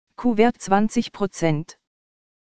Anbei fehlende Systemsounds.